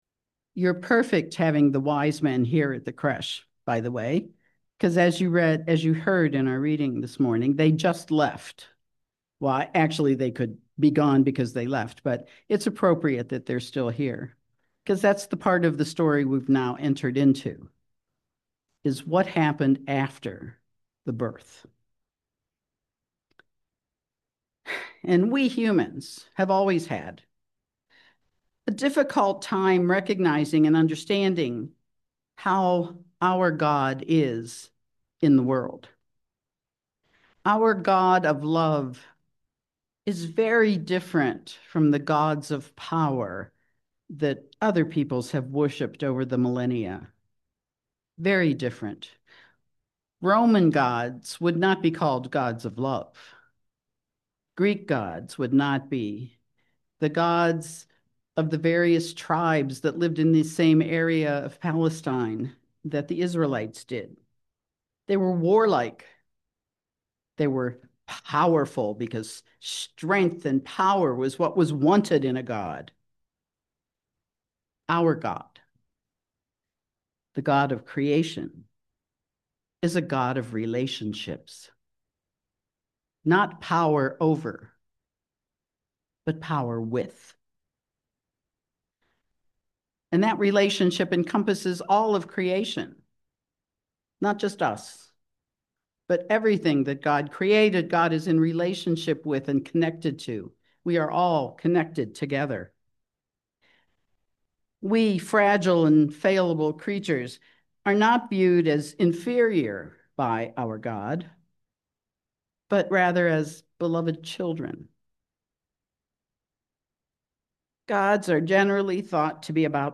Guest message